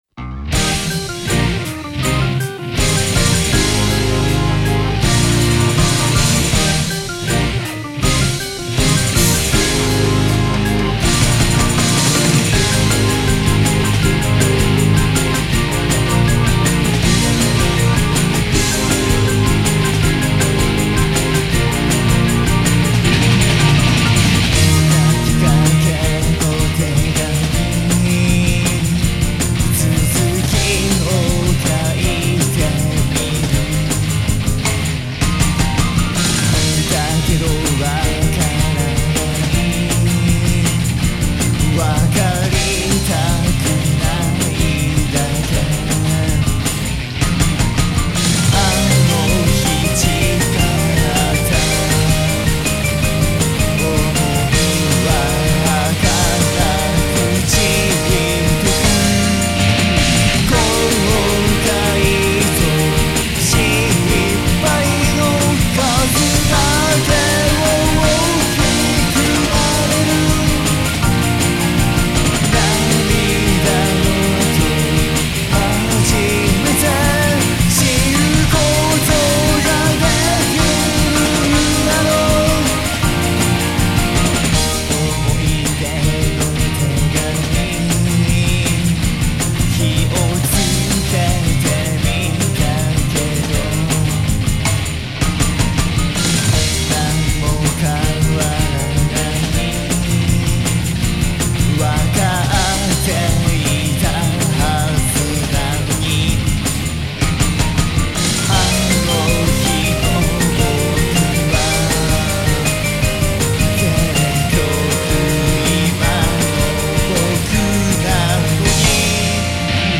あっそうだこの曲もSX使ったんだをふと思い出しました。
特にソロとかは、良く分からん音出してますW
この時は、アンプはV-AMPだし、DAWもSSW
今も音痴ですが、より音痴ですW